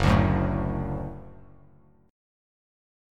Gbm#5 chord